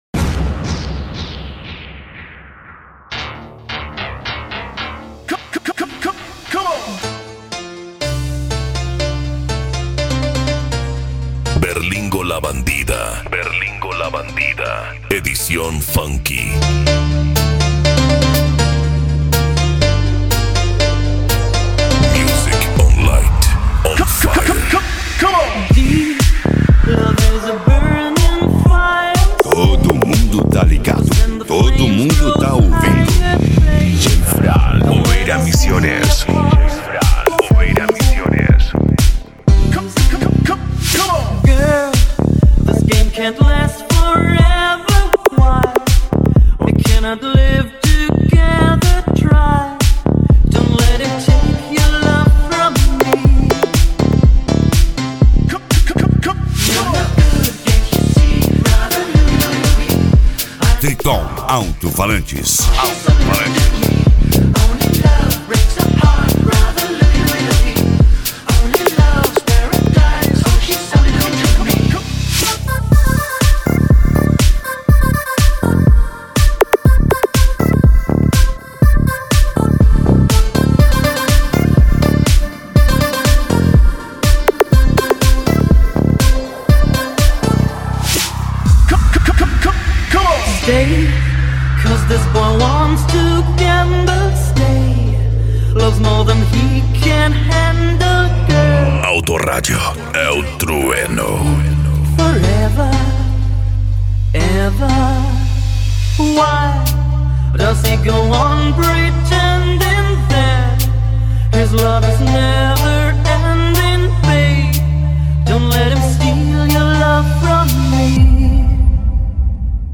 Funk
Mega Funk
Remix